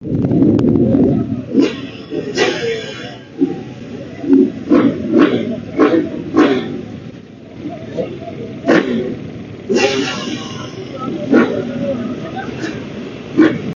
motocross-2.mp3